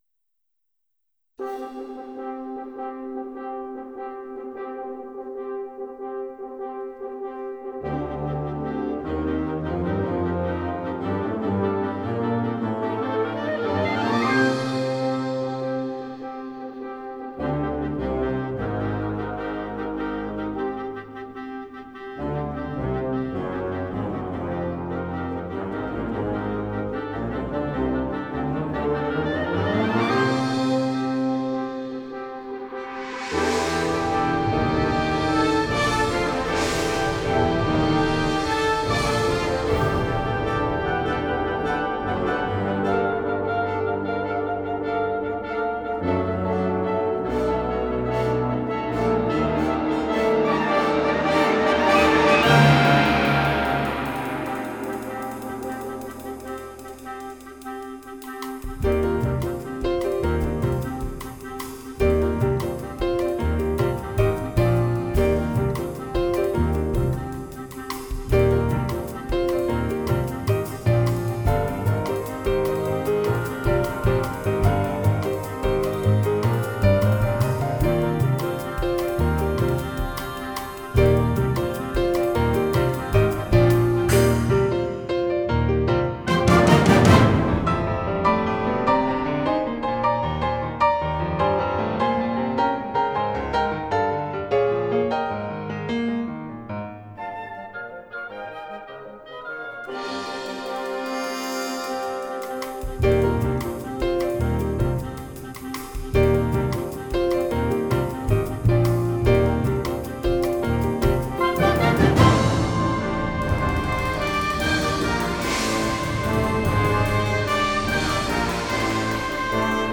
Concert Band plus piano, bass, drums